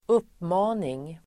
Uttal: [²'up:ma:ning]
uppmaning.mp3